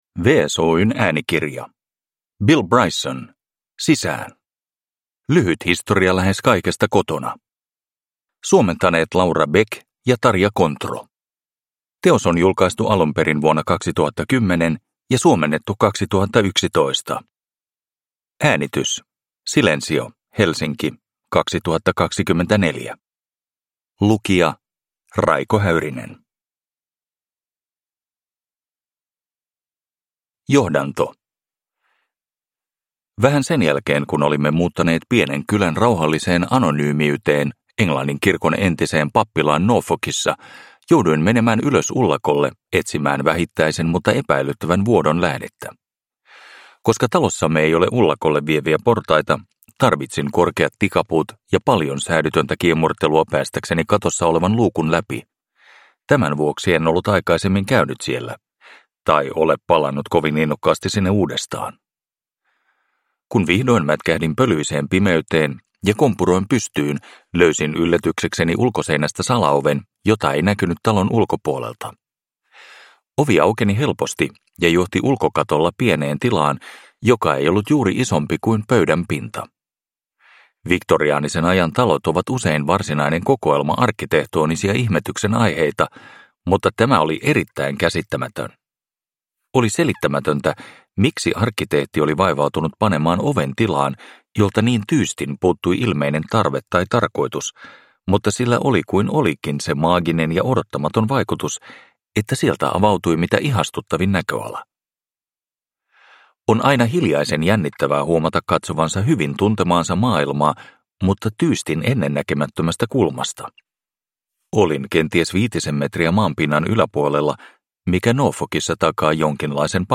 Sisään! – Ljudbok